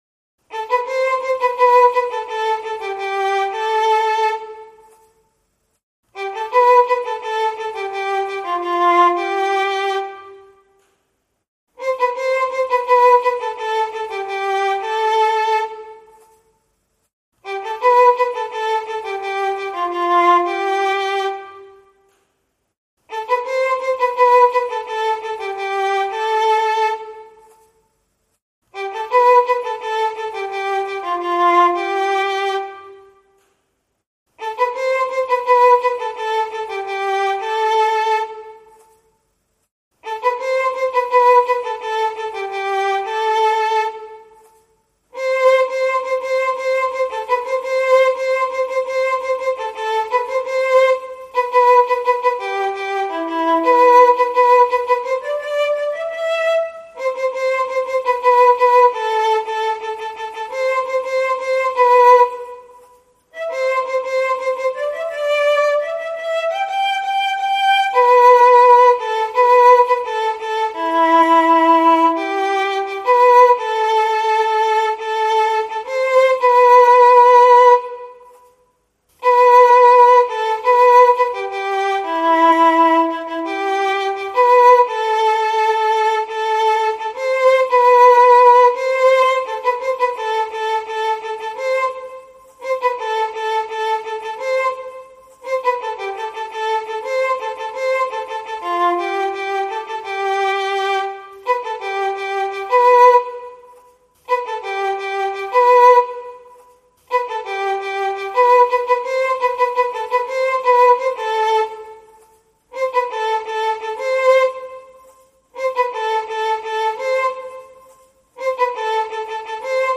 ویولون